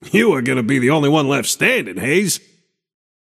Shopkeeper voice line - You are gonna be the only one left standin‘, Haze.